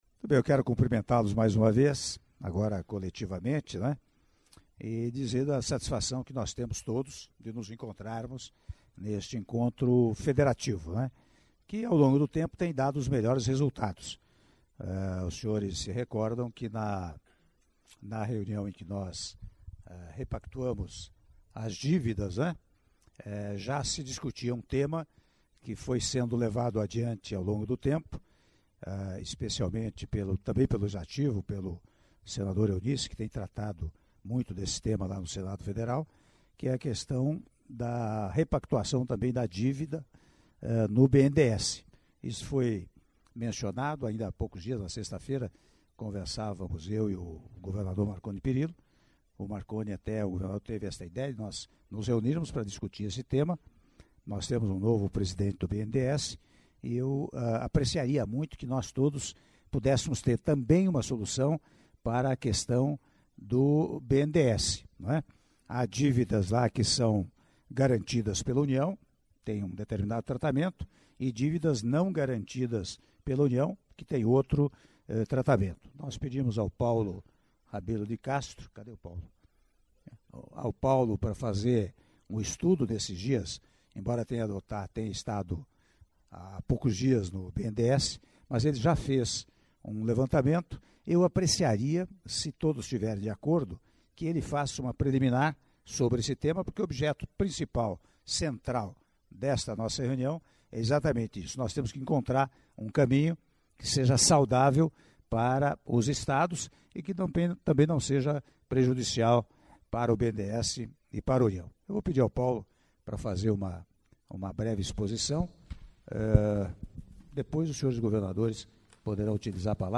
Áudio das Palavras do Presidente da República, Michel Temer, durante jantar com Governadores - Brasília/DF (02min01s)